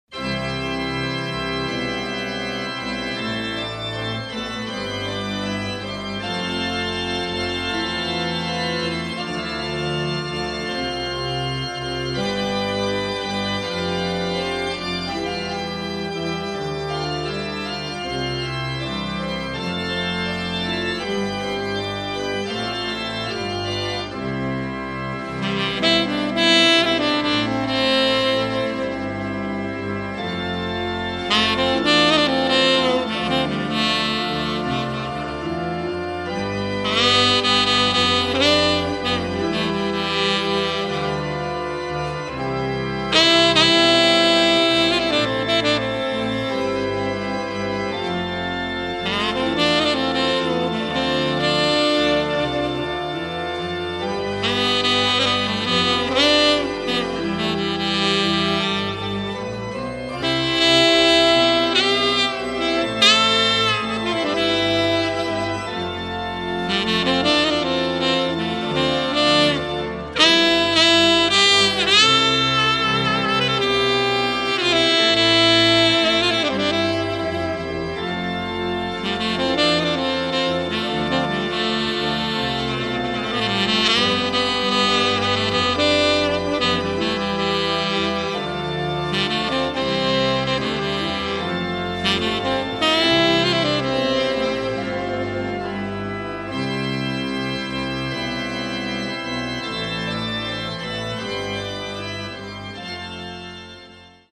Orgel
Trompete
Querflöte, Sopransax
Violine